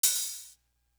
Still Feel Me Open Hat.wav